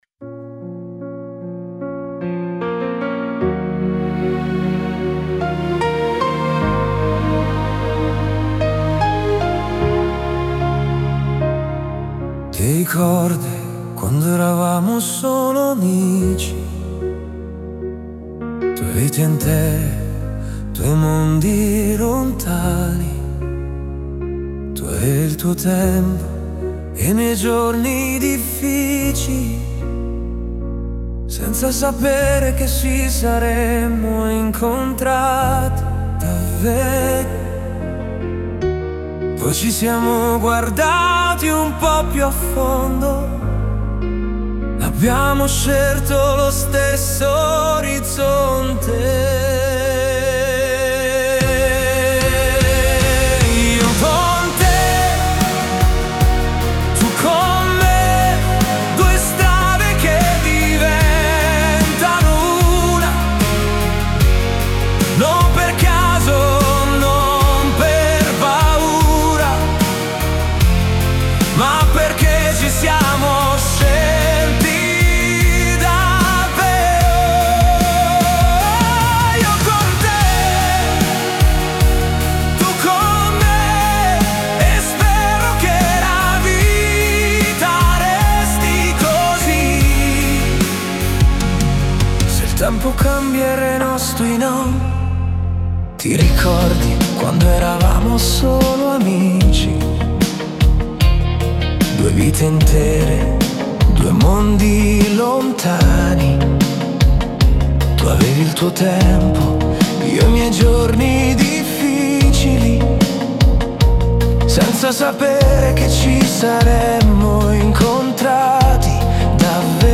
lento
Lento
Una canzone pop